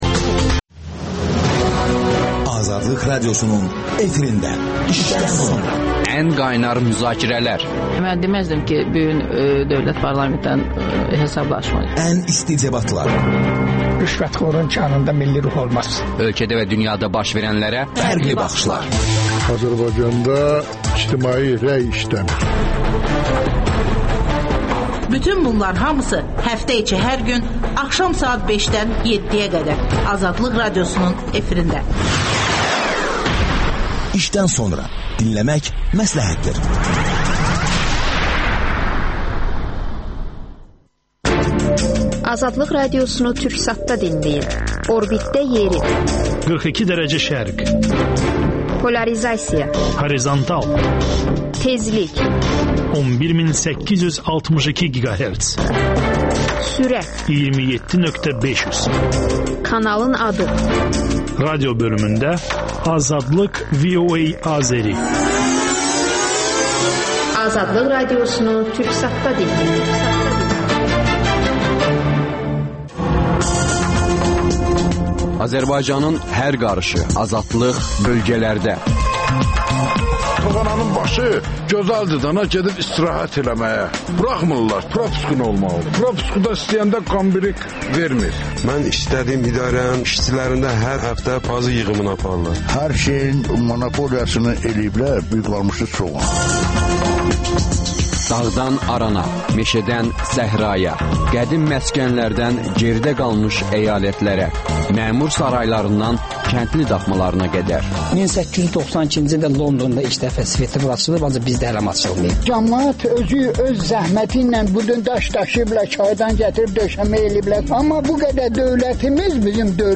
hərbi ekspert